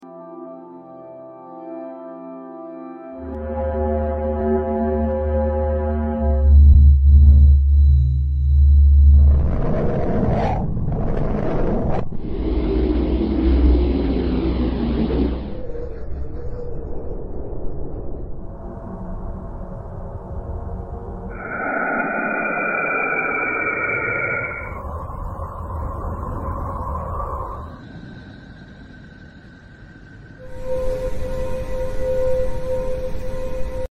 This is How all Planets sounds from Space A friendly Reminder, None of the sounds are Fake, They are created By LIGO observatory lab after fetching tons of raw data of wave lengths coming directly from the respective planets.